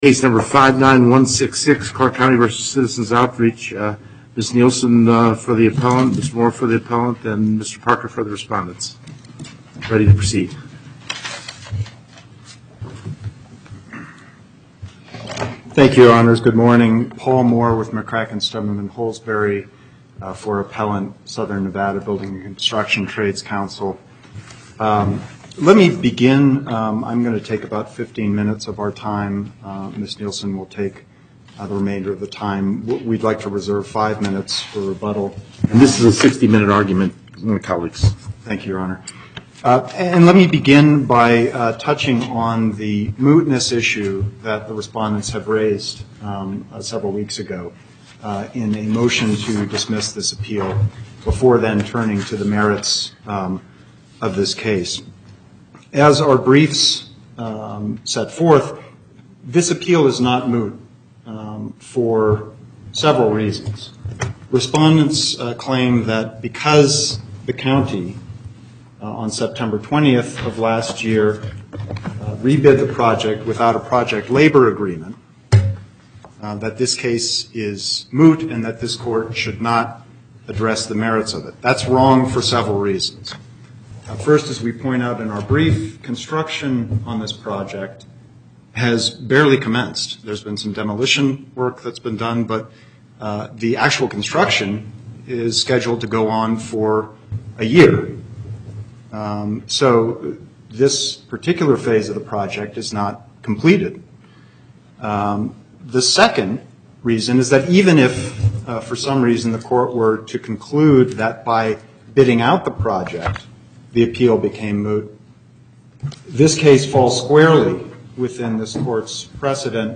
Location: Carson City En Banc Court, Chief Justice Cherry Presiding